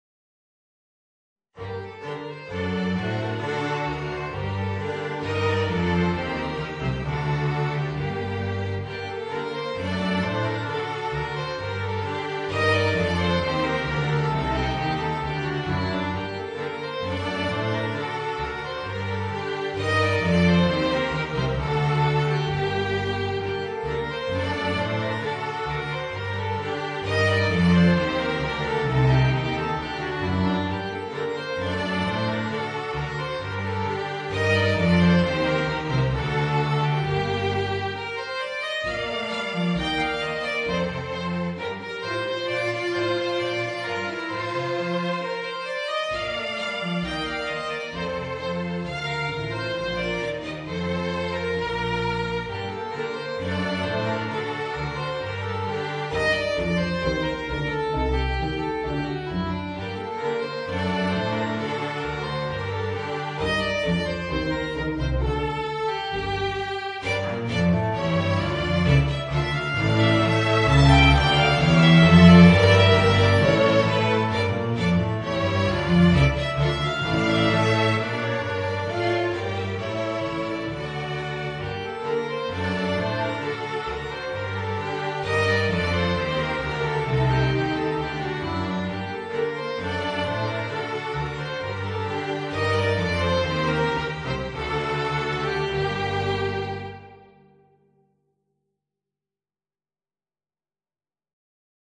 Voicing: Flute and String Quintet